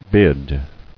[bid]